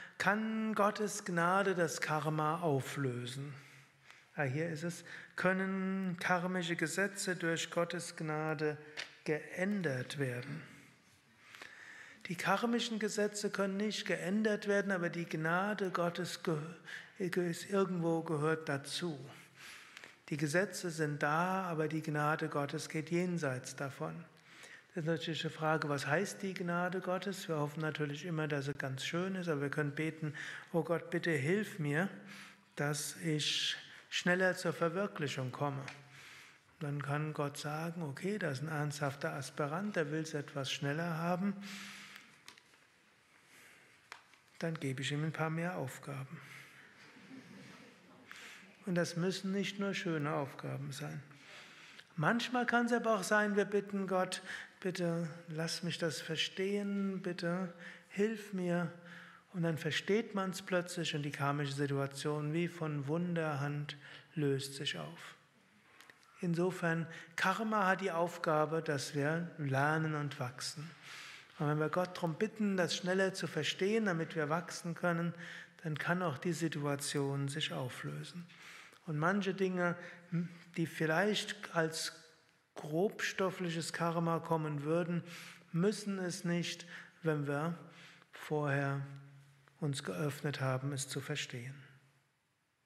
Kurzvorträge
Aufnahme während eines Satsangs gehalten nach einer Meditation im